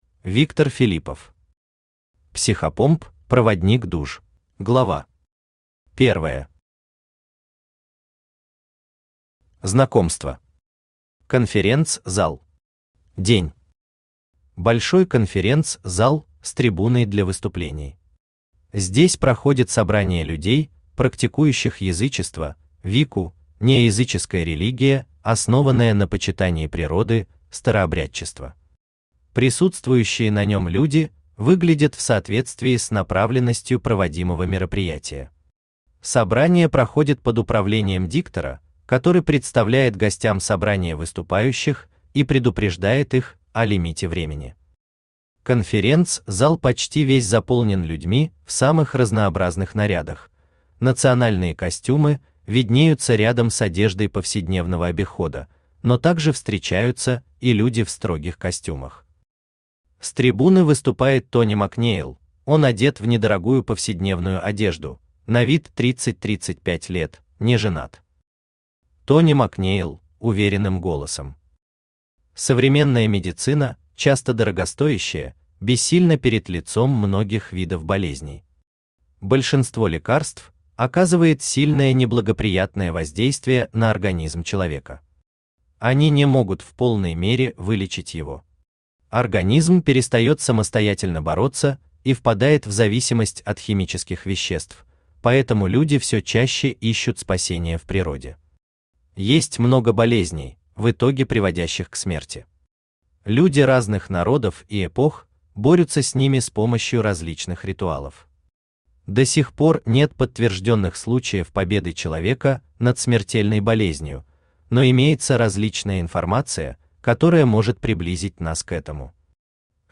Аудиокнига Психопомп: проводник душ | Библиотека аудиокниг
Aудиокнига Психопомп: проводник душ Автор Виктор Филиппов Читает аудиокнигу Авточтец ЛитРес.